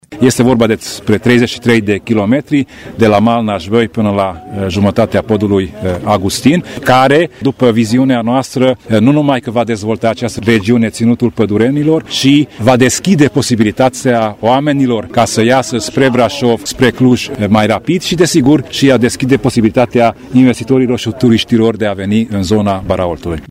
La rândul său, președintele CJ Covasna, Tamas Sandor, a vorbit despre avantajele proiectului de modernizare a drumului: